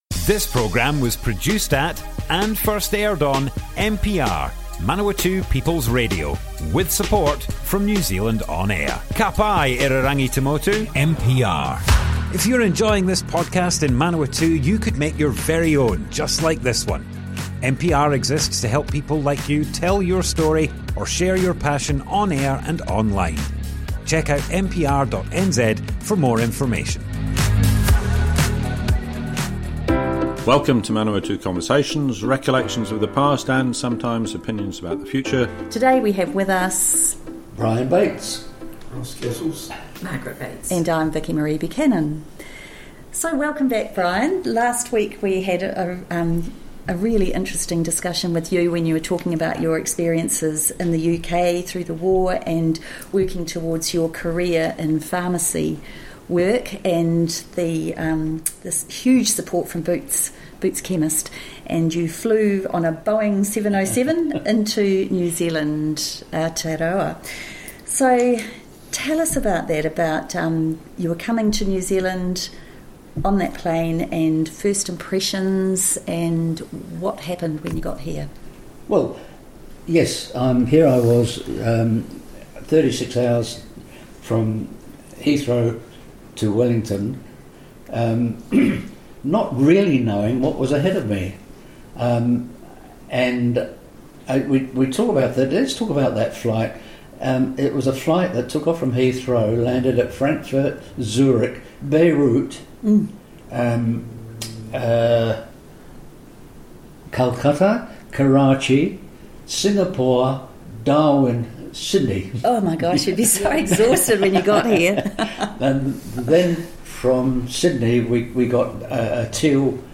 Broadcast on Manawatu People’s Radio, 31st October 2023.